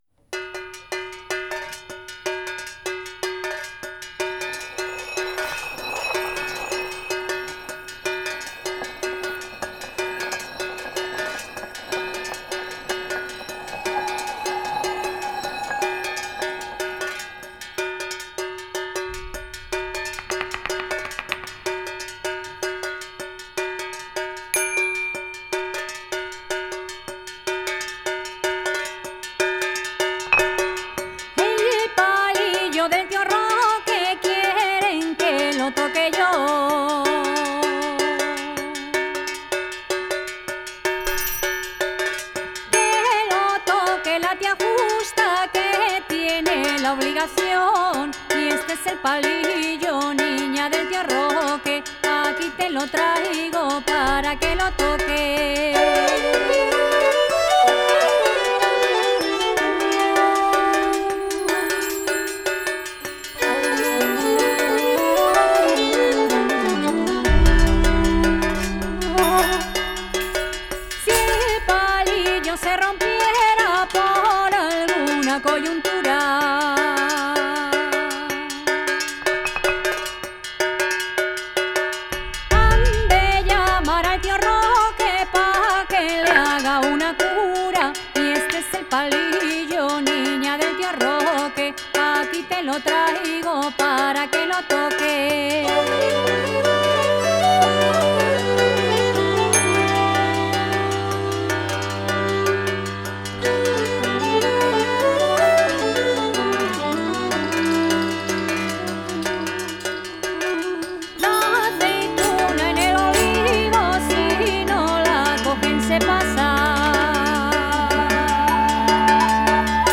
dos referentes esenciales de la música de raíz europea.